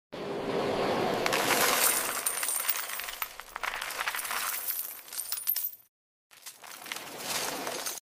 Satisfying ASMR videos of Dollar's